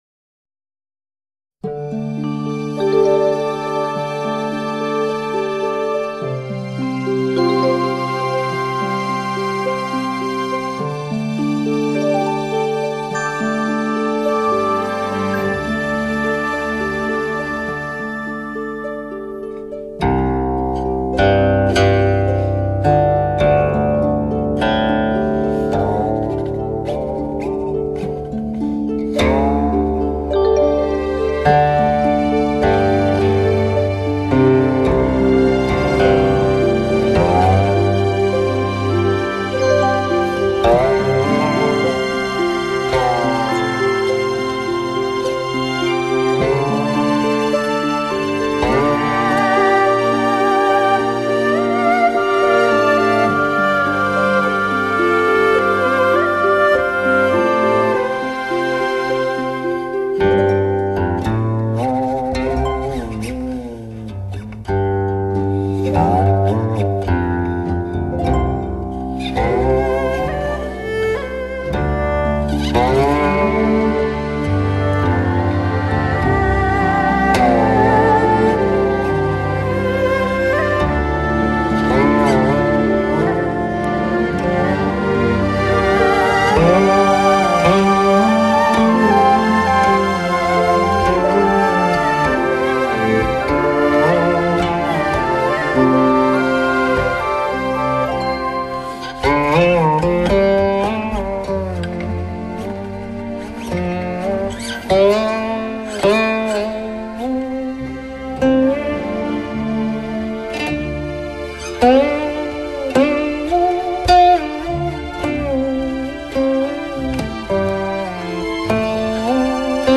是一款别具特色的古琴录音
妙在尝试将编制完整的西洋管弦大乐队与古琴巧妙配合